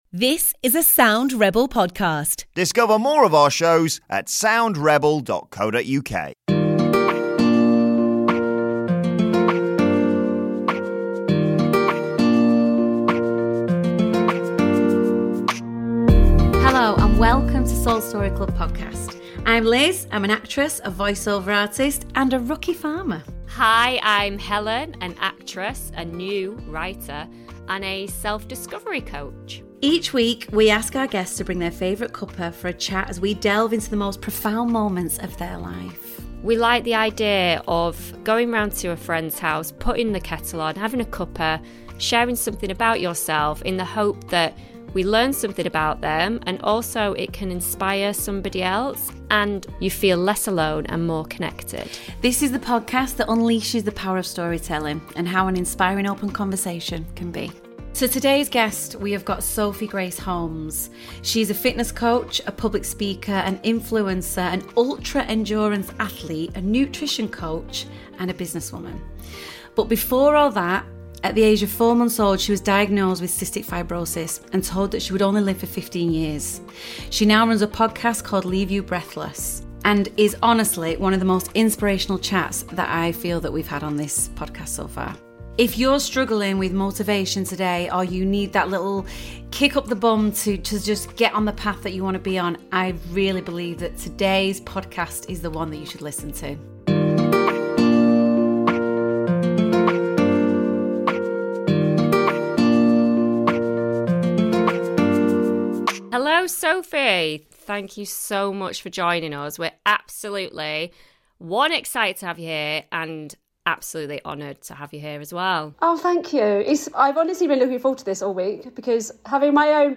it’s such a positive, uplifting chat!